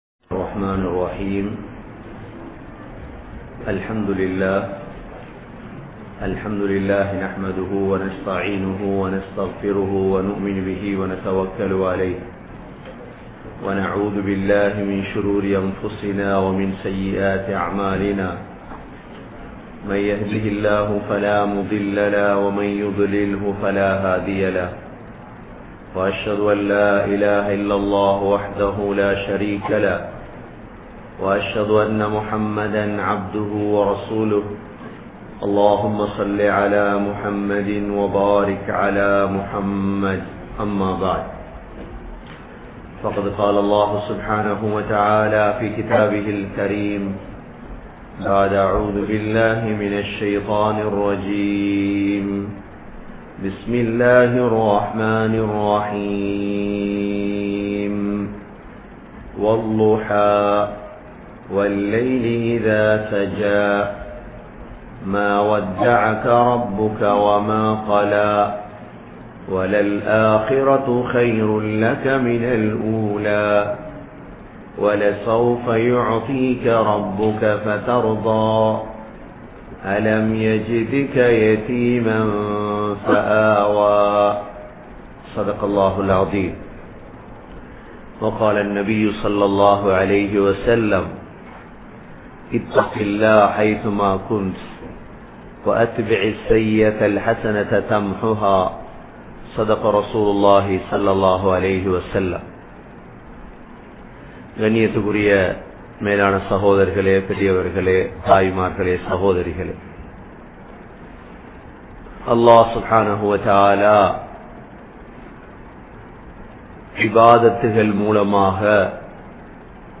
Allah`vai Payanthu Kollugal | Audio Bayans | All Ceylon Muslim Youth Community | Addalaichenai